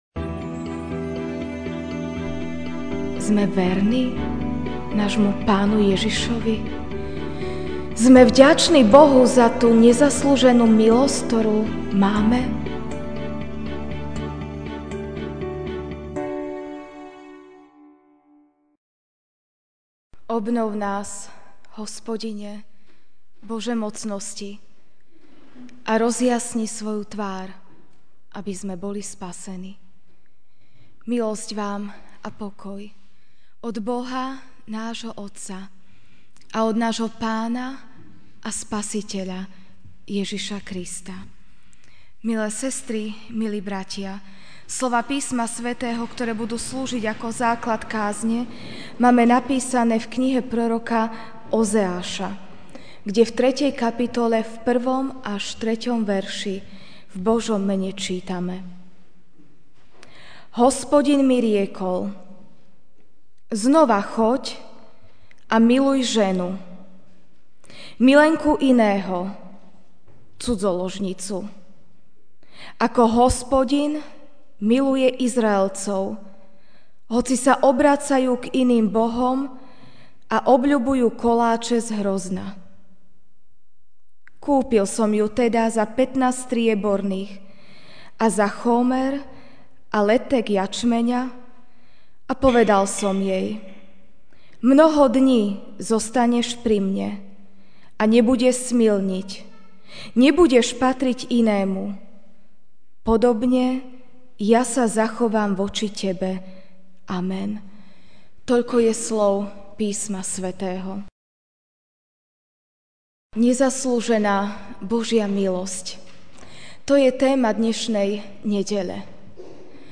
Ranná kázeň: Nezaslúžená milosť (Ozeáš 3, 1-3) Hospodin mi riekol: Znova choď a miluj ženu, milenku iného, cudzoložnicu, ako Hospodin miluje Izraelcov, hoci sa obracajú k iným bohom a obľubujú koláče z hrozna.